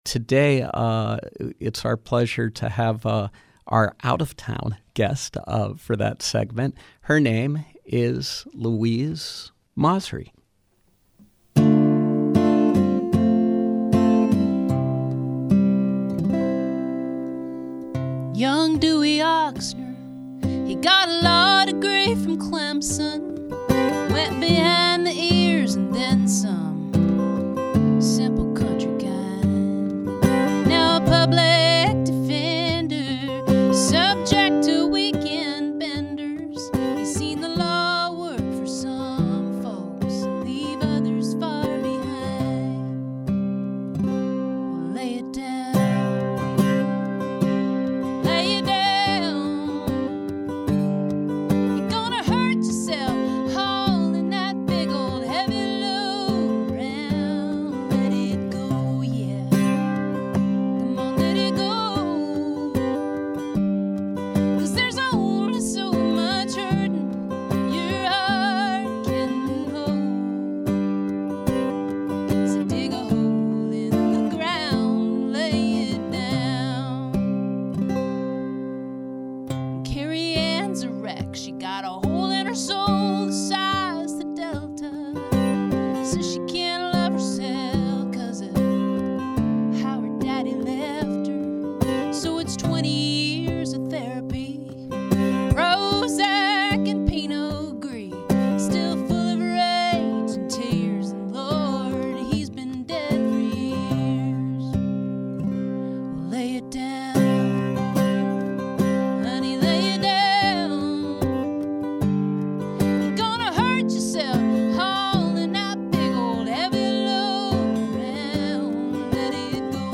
A special live music segment
singer/songwriter